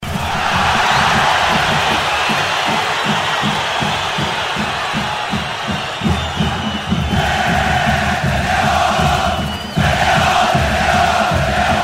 grito da sound effects
grito-da